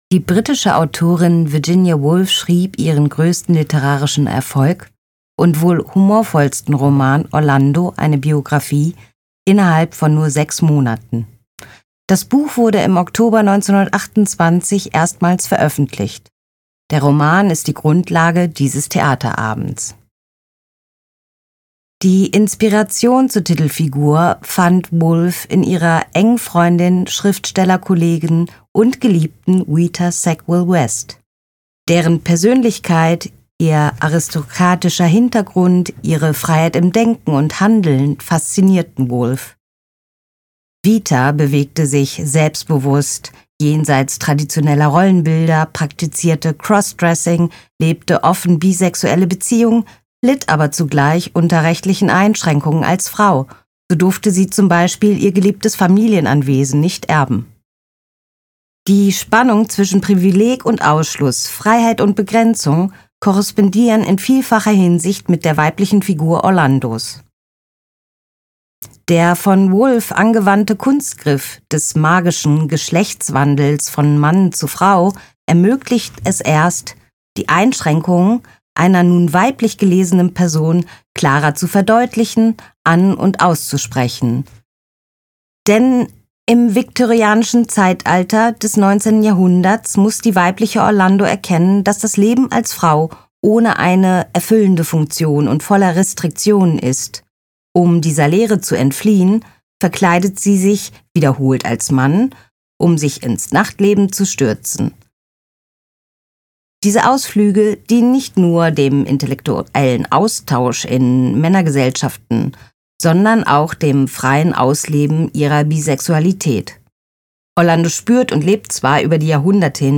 hoereinfuehrung_Orlando.mp3